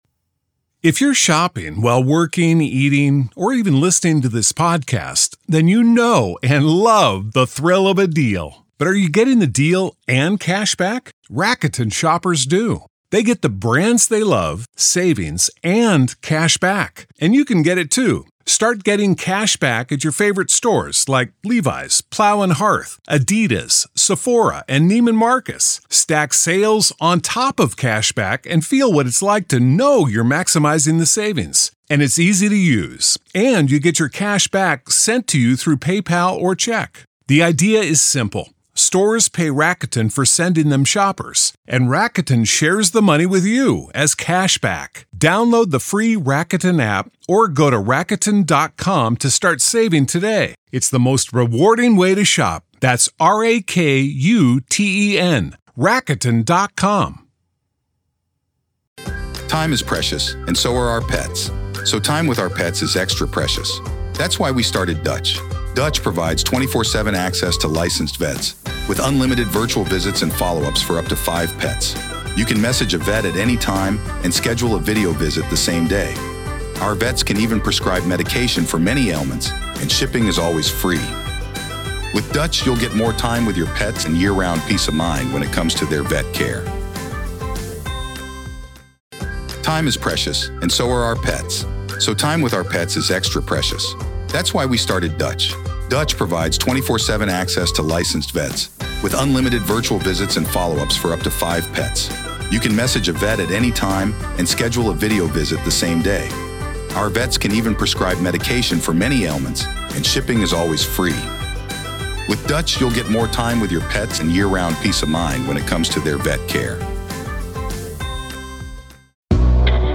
Court Audio-NV v. Robert Telles DAY 1 Part 4